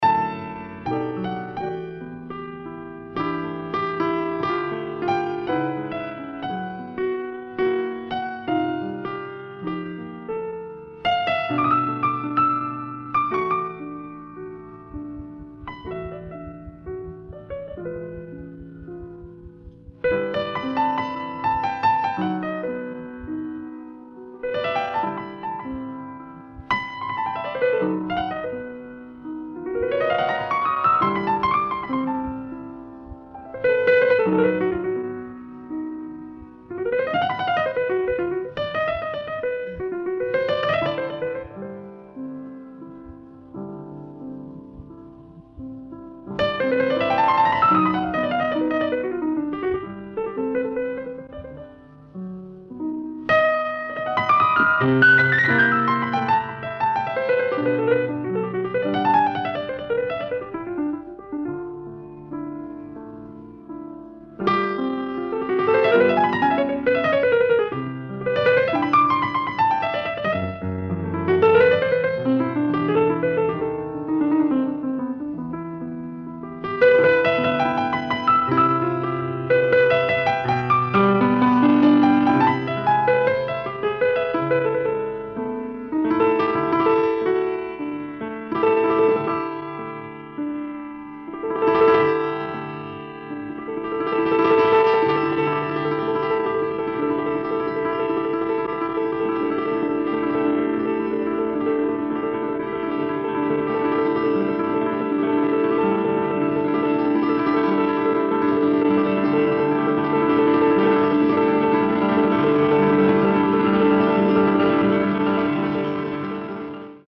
※試聴用に実際より音質を落としています。
Piano Improvisation